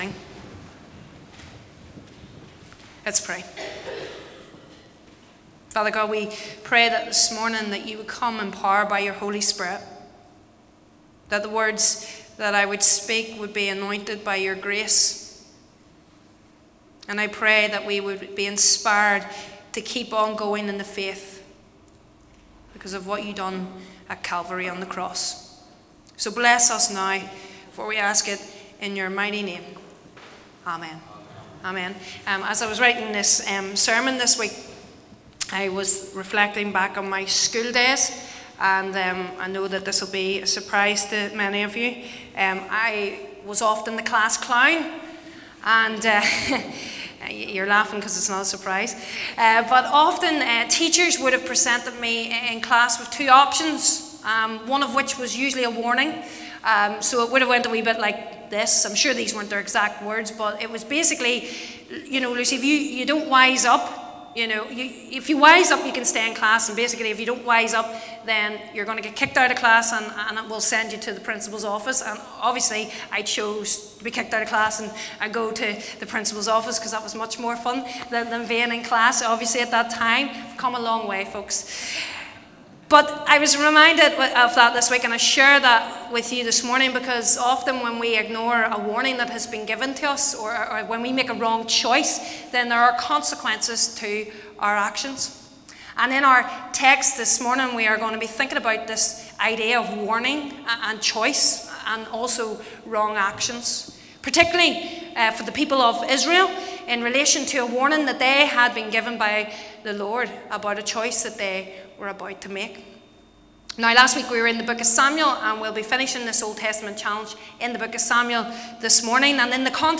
23rd-Feb_Sermon.mp3